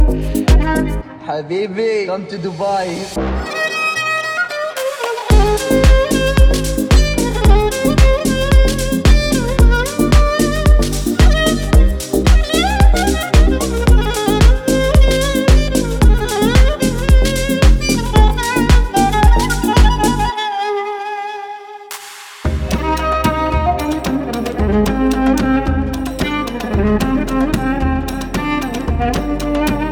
Жанр: Хаус